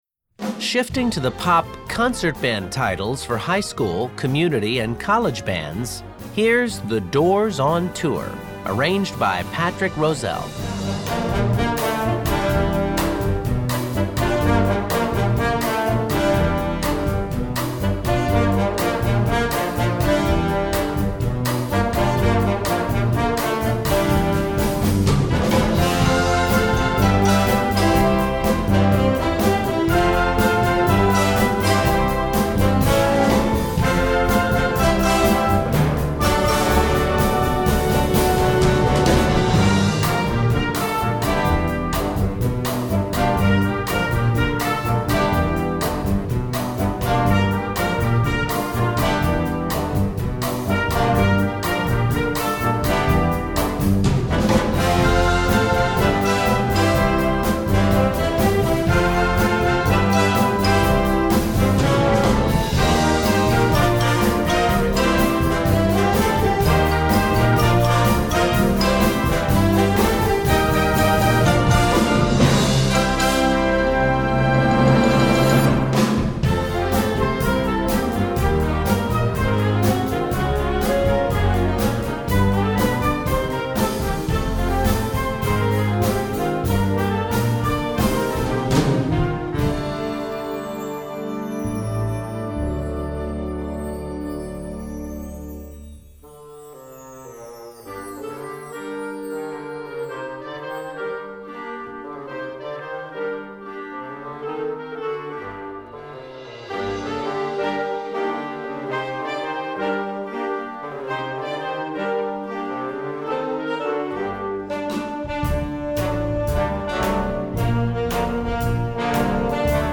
Besetzung: Blasorchester
is back in this arrangement for your concert band